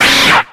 d3662c3f10a658bbe1382c1d2b1a54bf123b6687 infinitefusion-e18 / Audio / SE / Cries / RATICATE.ogg infinitefusion d3662c3f10 update to latest 6.0 release 2023-11-12 21:45:07 -05:00 7.5 KiB Raw History